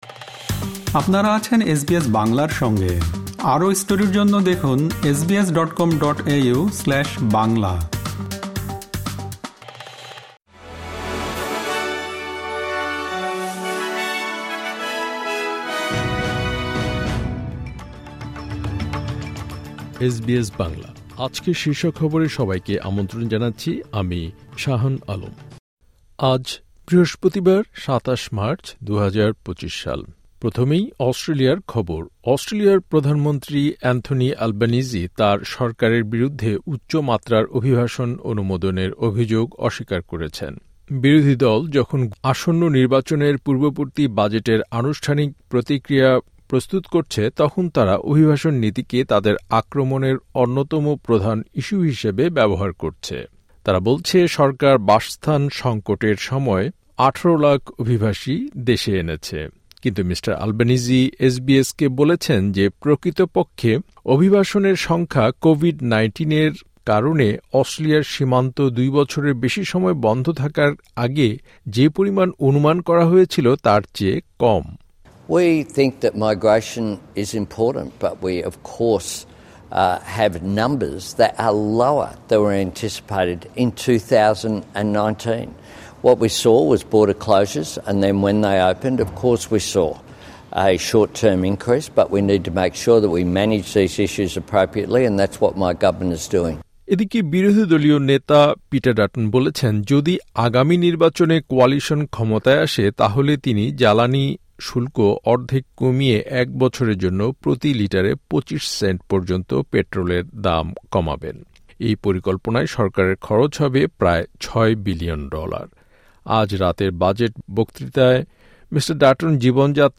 এসবিএস বাংলা শীর্ষ খবর: ২৭ মার্চ, ২০২৫